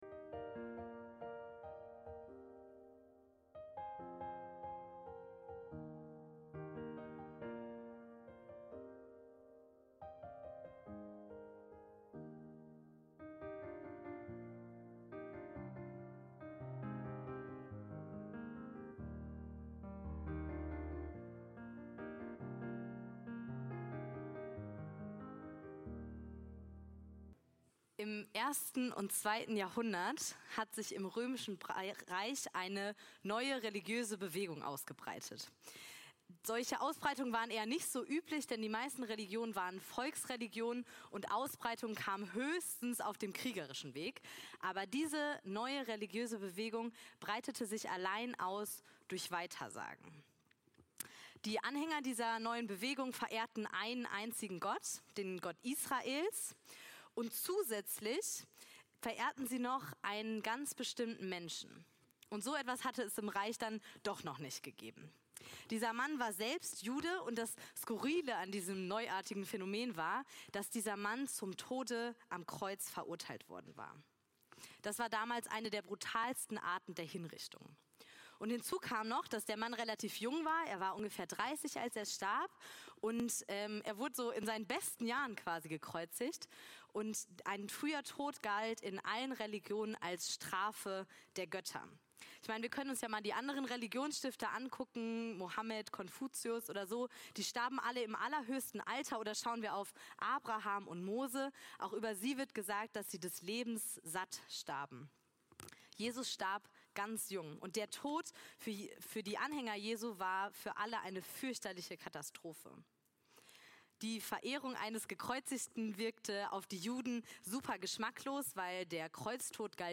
Predigt vom 18.04.2025 Jesus - König, Priester und Prophet